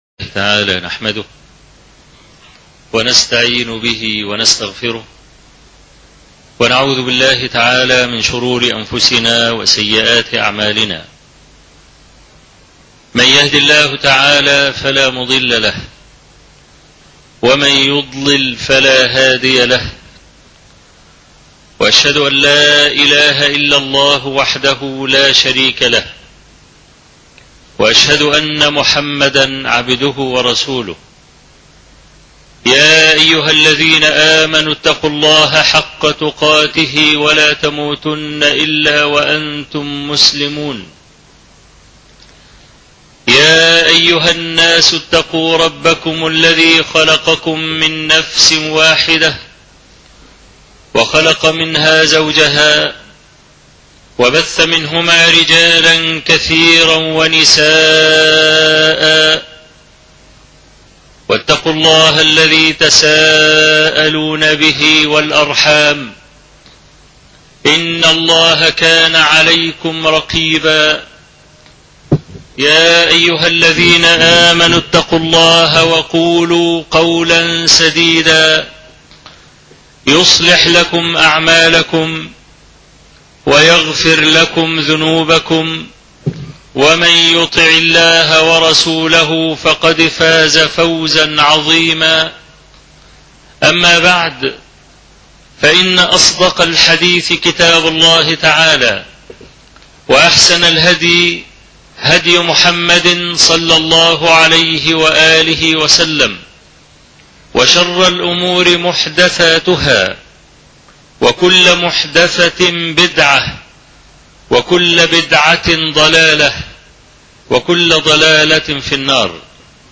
الخطبة الأولى (سلم الصعود)